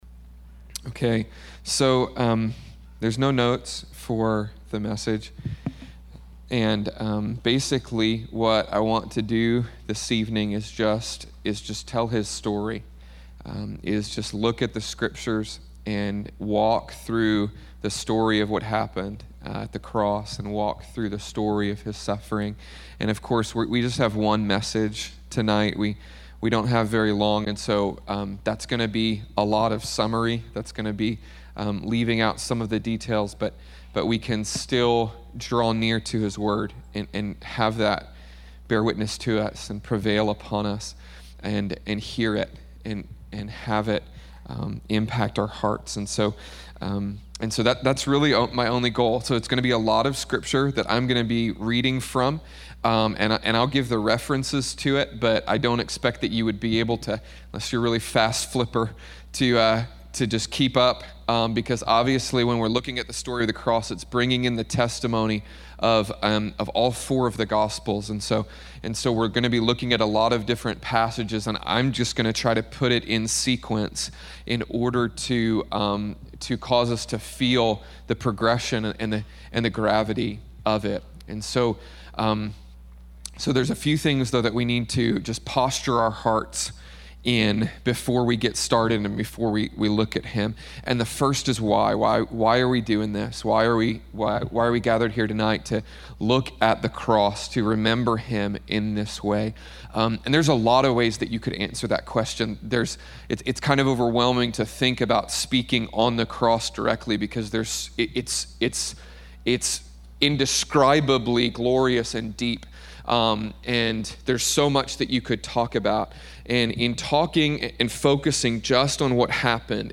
Have this cry of heaven evoked in your own heart as you gaze upon Jesus and the story of His crucifixion. This message was spoken on Good Friday during the Encounter God Service at IHOPKC.